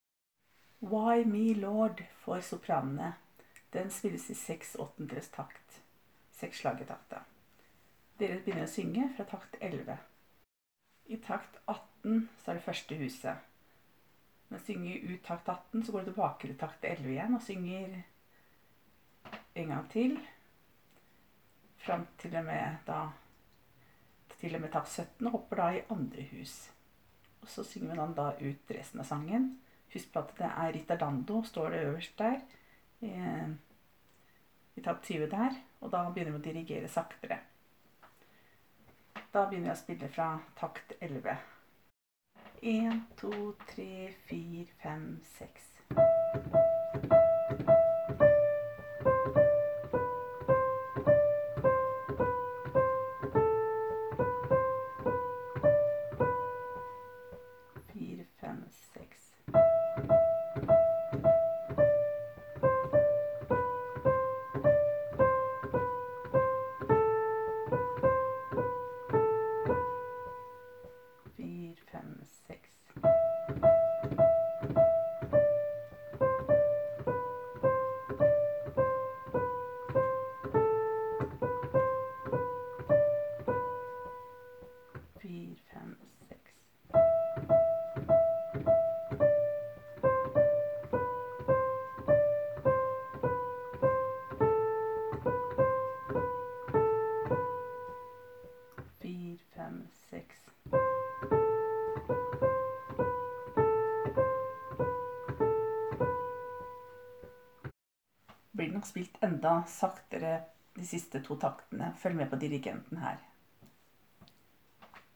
1-2.Sopran. Why me lord: